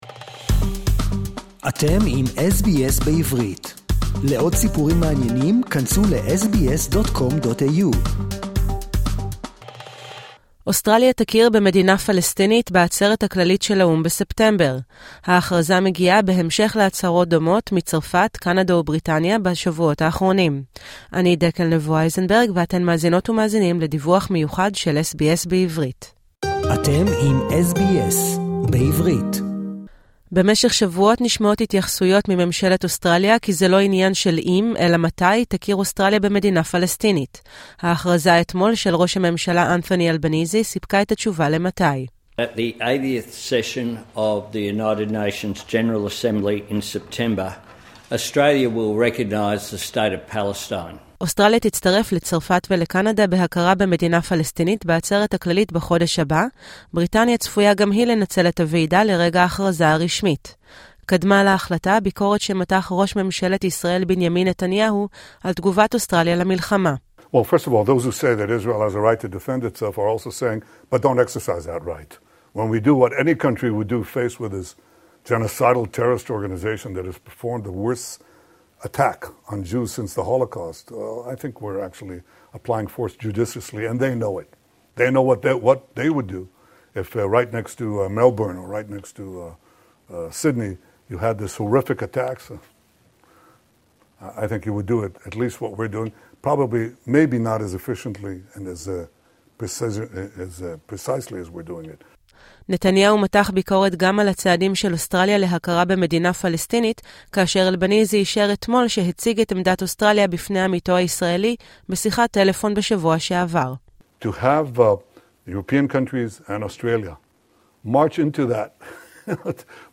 ממשלת אוסטרליה מצהירה כי תכיר במדינה פלסטינית בעצרת הכללית של האו"ם בחודש הבא. דיווח מיוחד מ-SBS בעברית